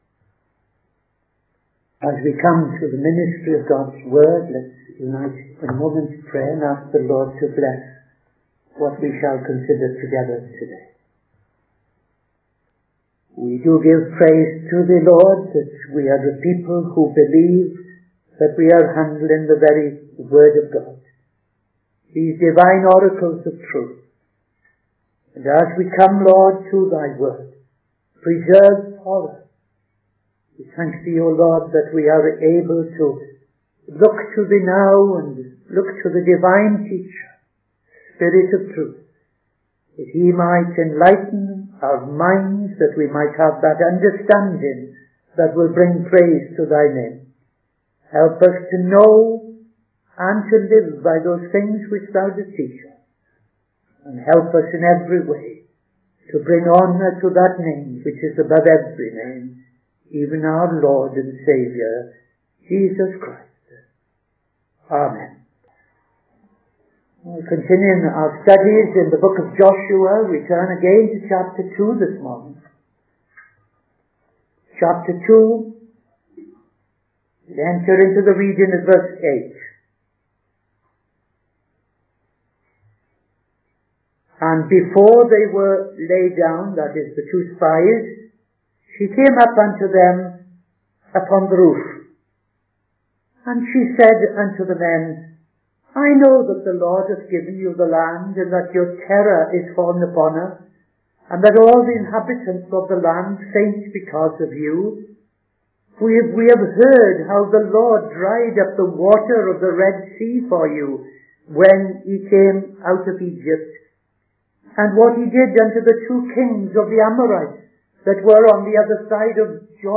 Midday Sermon - TFCChurch